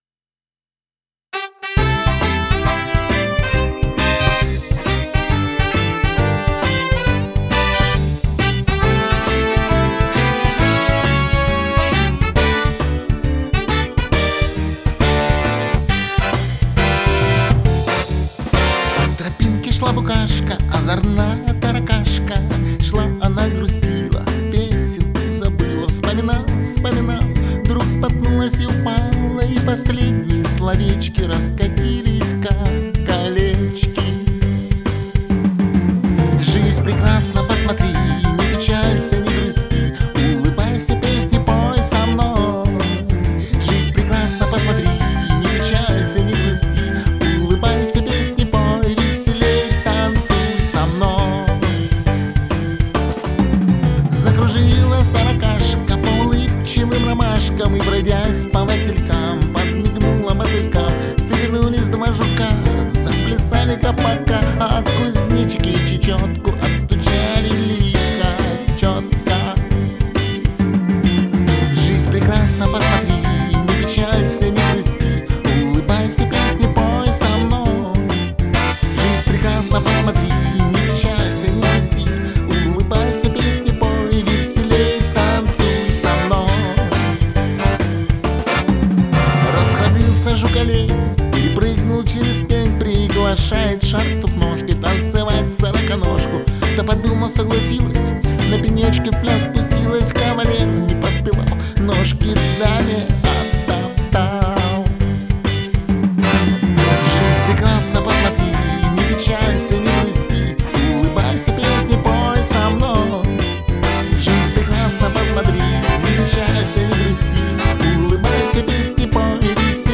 Детская песня